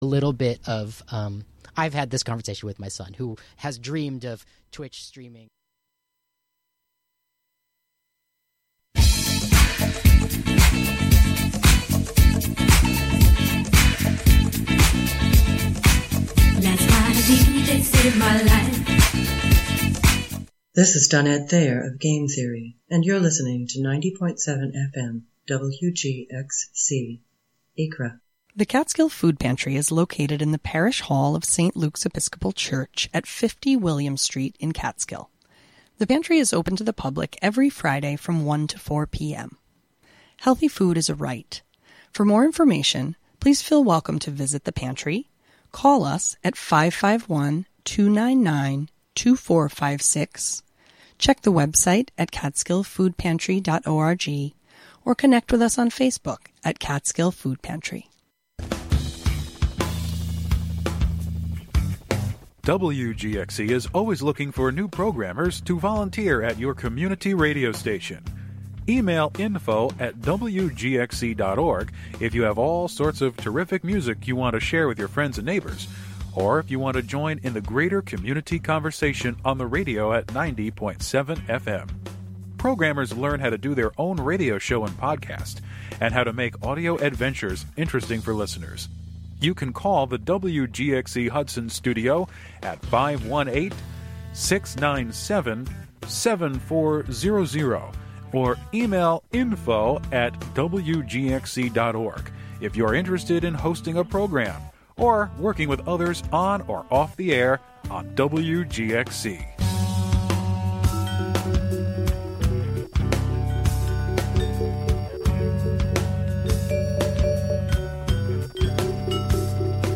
"Auditions" features prospective WGXC volunteer programmers trying out their proposed radio programs on air for listener feedback.